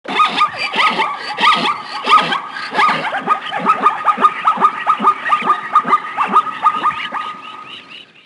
zebra5.wav